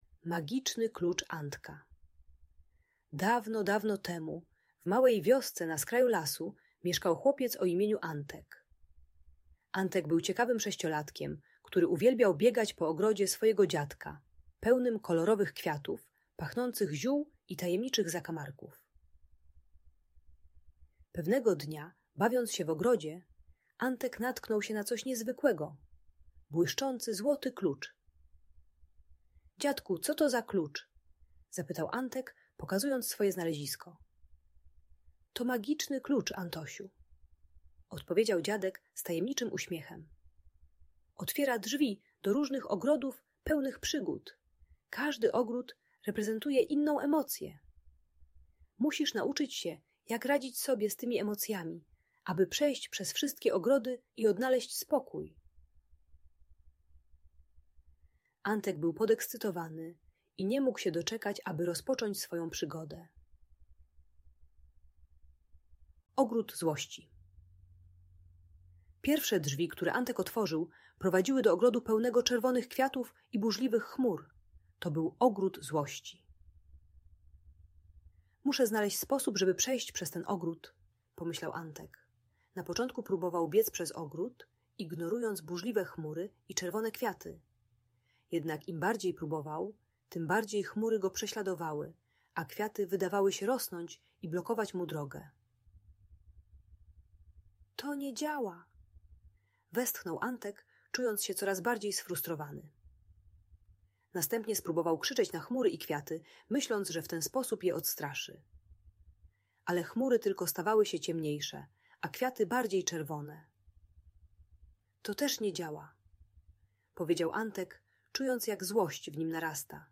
Magiczny Klucz Antka - Opowieść o emocjach - Audiobajka